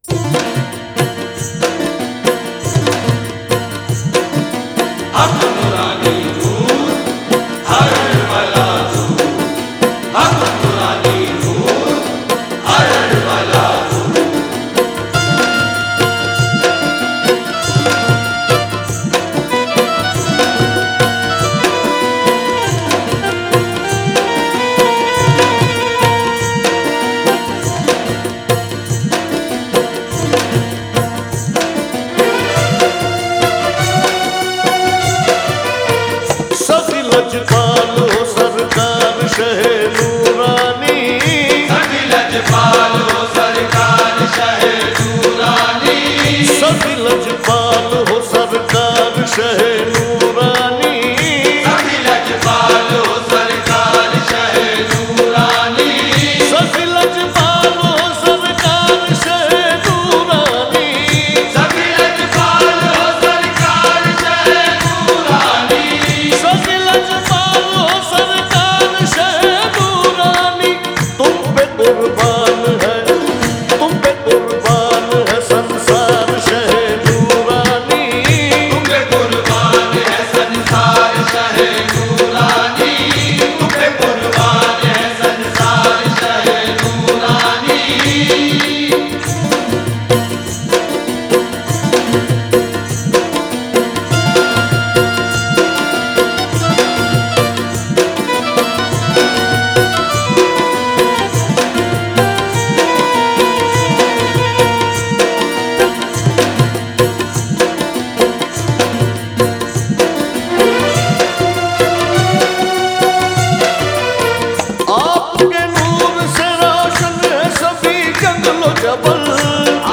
Download MP3 Worlds Largest Collection of Qawwali